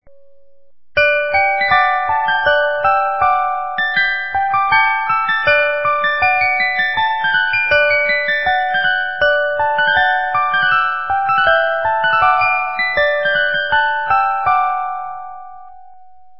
18 Note Musical Movements
with Winding Key